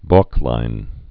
(bôklīn)